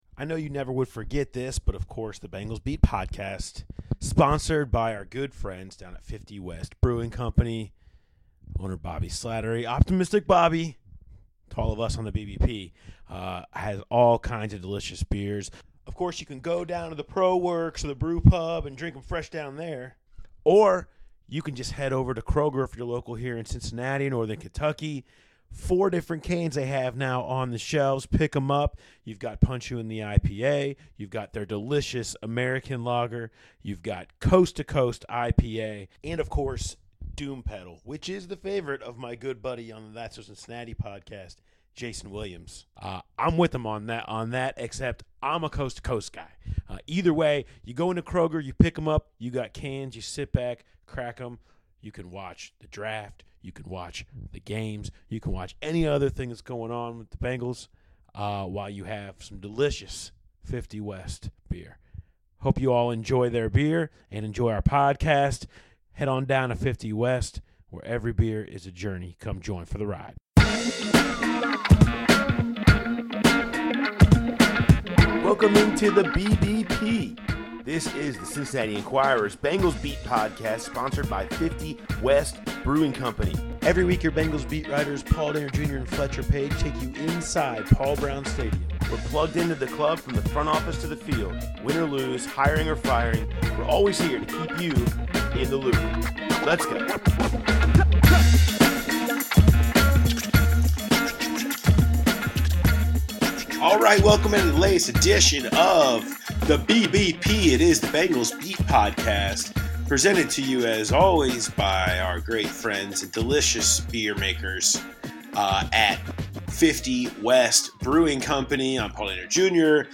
offer up 15 minutes of the conversation with the current Rams quarterbacks coach Zac Taylor from the Super Bowl in Atlanta.